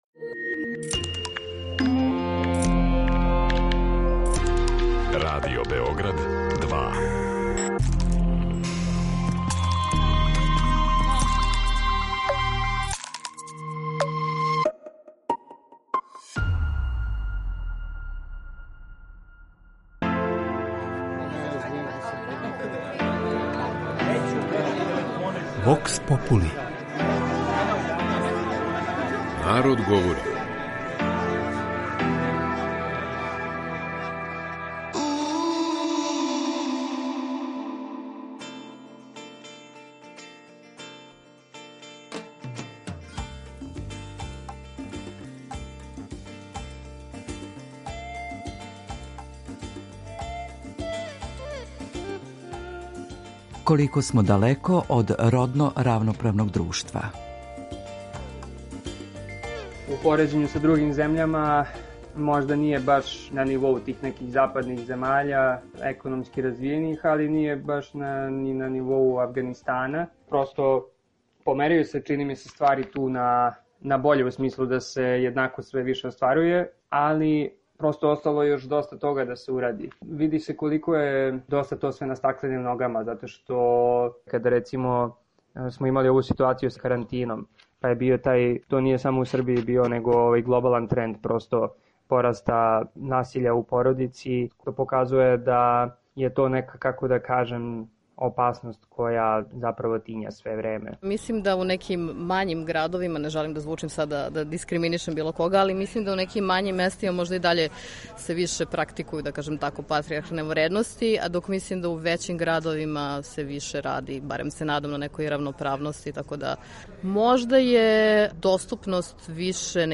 Вокс попули